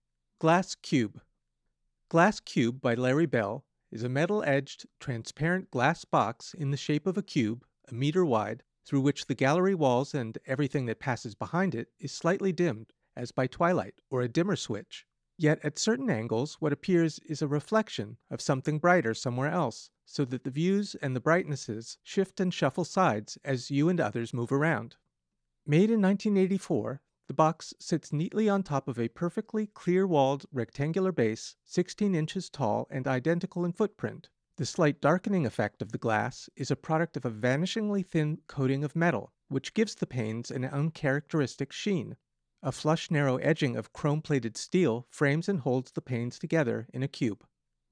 Audio Description (00:48)